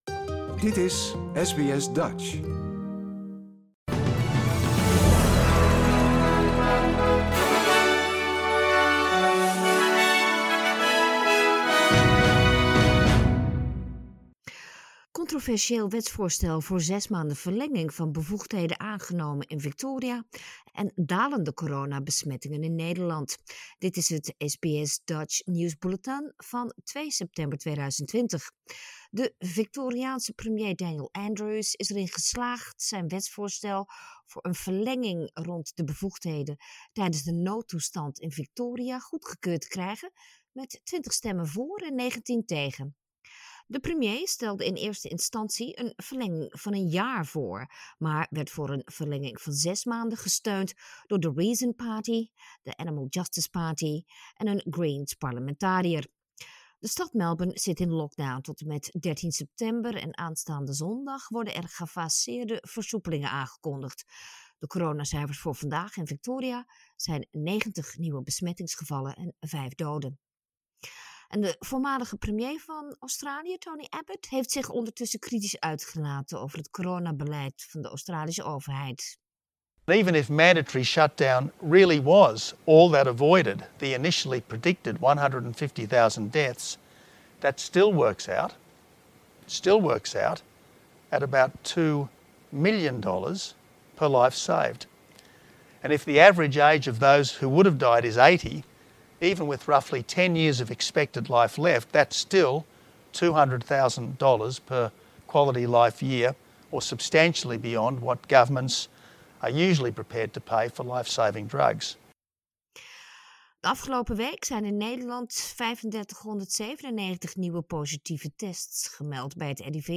Nederlands/Australisch SBS Dutch nieuws bulletin woensdag 2 september 2020